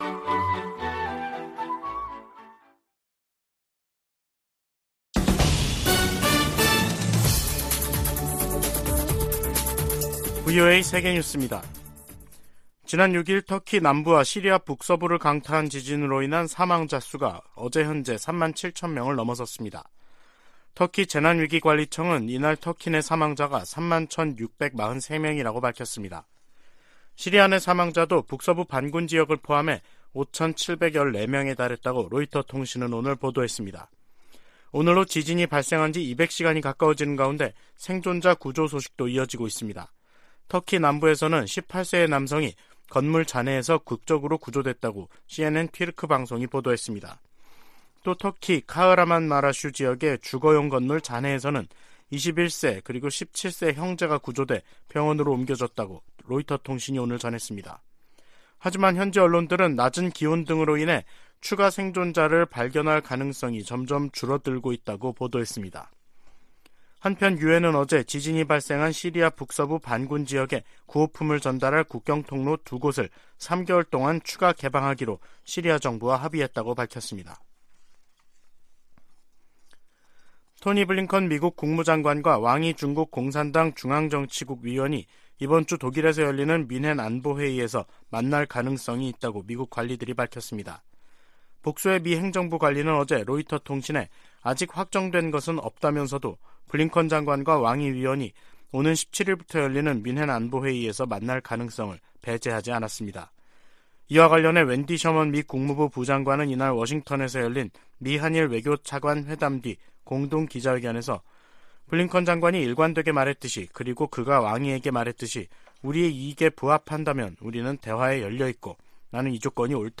VOA 한국어 간판 뉴스 프로그램 '뉴스 투데이', 2023년 2월 14일 2부 방송입니다. 미국과 한국, 일본의 외교 차관들이 워싱턴에서 회의를 열고 북한의 핵과 미사일 위협에 대응해 삼각 공조를 강화하기로 했습니다. 미국 정부는 중국 등에 유엔 안보리 대북 결의의 문구와 정신을 따라야 한다고 촉구했습니다. 백악관은 중국의 정찰풍선이 전 세계 수십 개 국가를 통과했다는 사실을 거듭 확인했습니다.